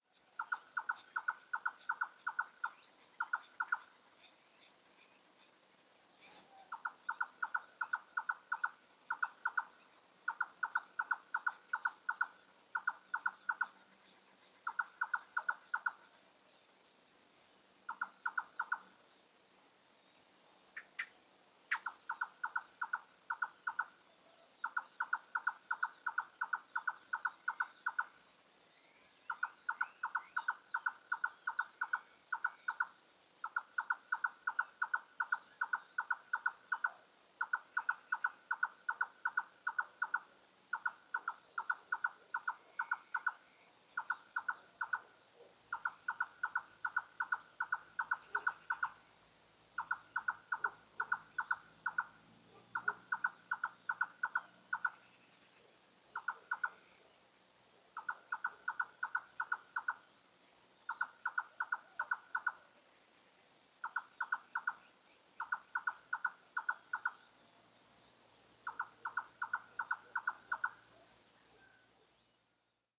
XC734015-noitibó-de-nuca-vermelha-Caprimulgus-ruficollis